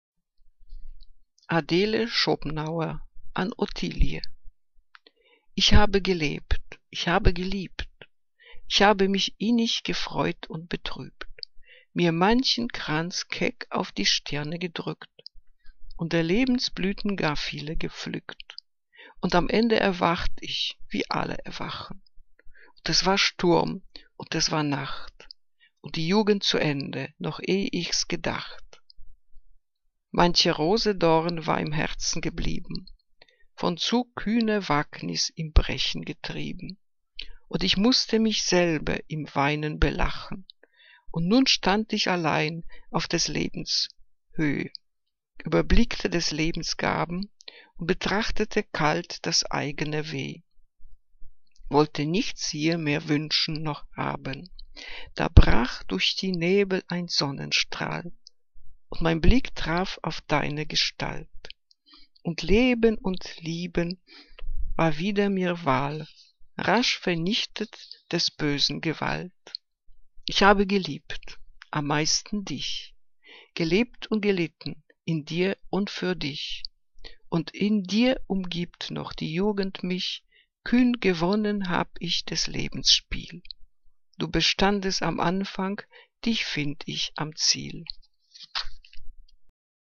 Liebeslyrik deutscher Dichter und Dichterinnen - gesprochen (Adele Schopenhauer)